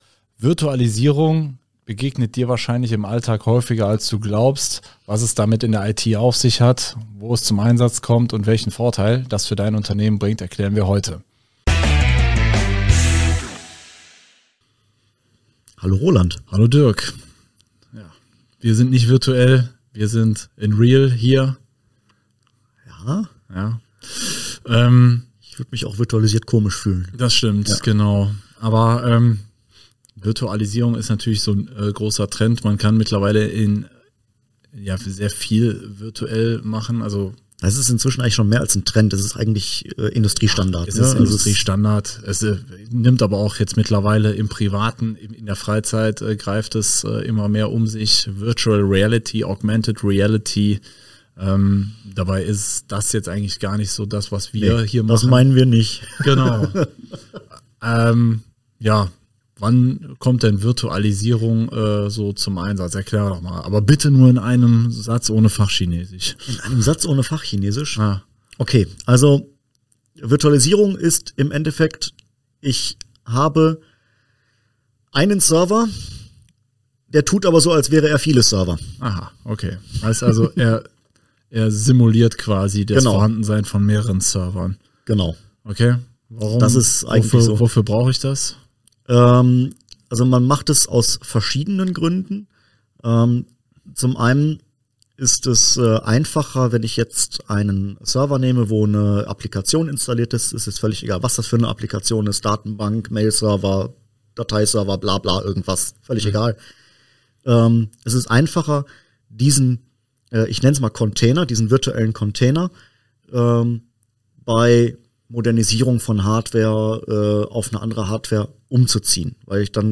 Um dir dieses IT-Wissen zu vermitteln, greifen wir auf eine Doppel-Besetzung zurück: